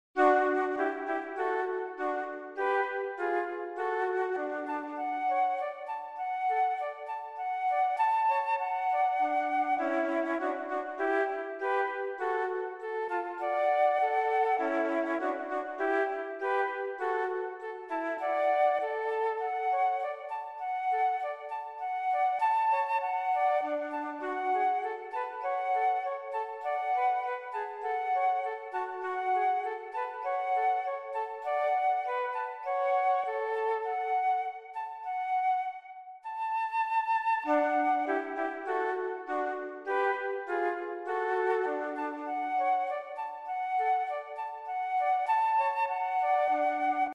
kleine, leichte Duette für 2 Flöten Partitur